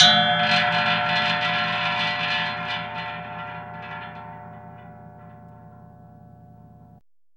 METAL HIT 10.wav